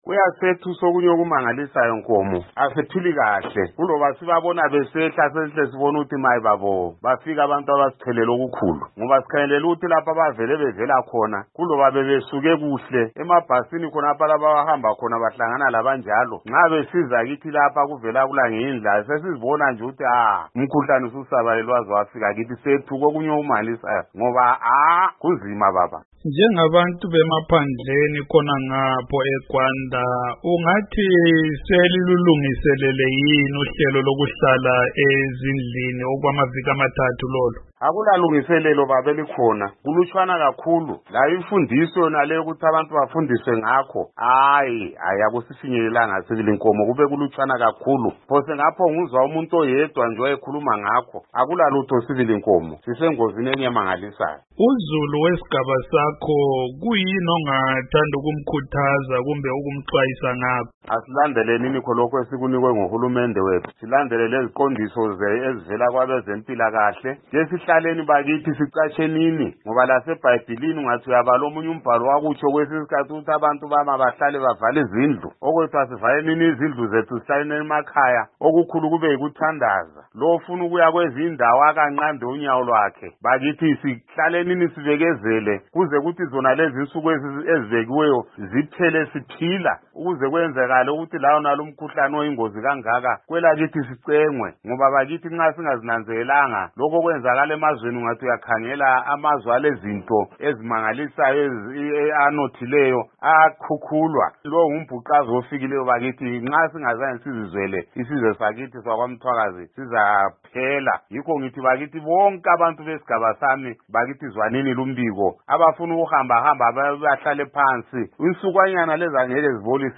Ingxoxo loCouncillor Miclas Ndlovu